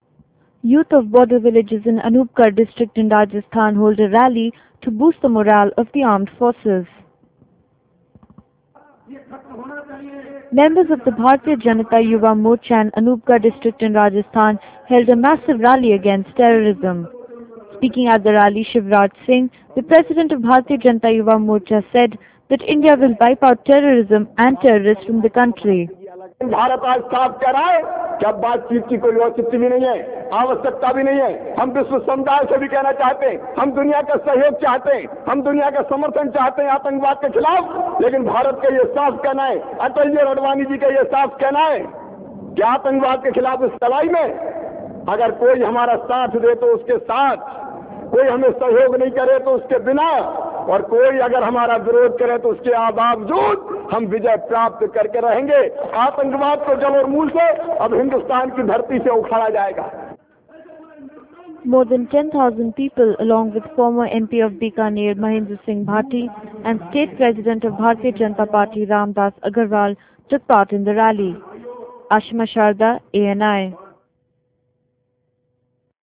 Youth of border villages in Anupgarh district, Rajasthan, hold a rally to boost the morale of armed forces.